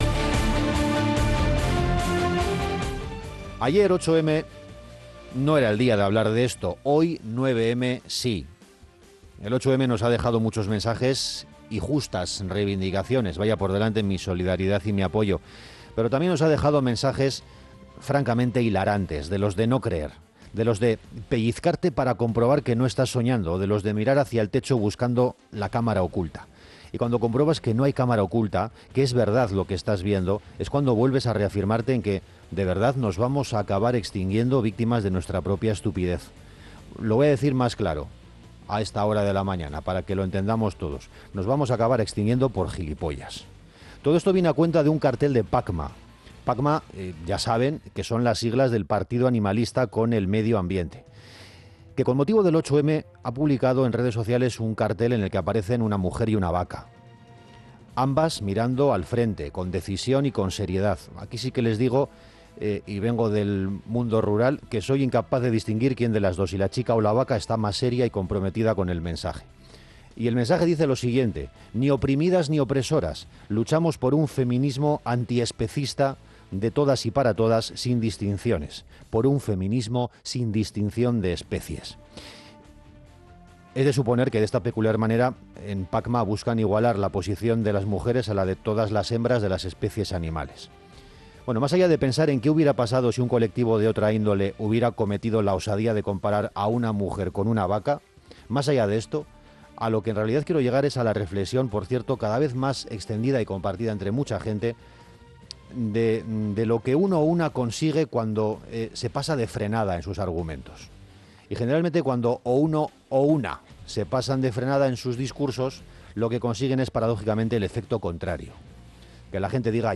El editorial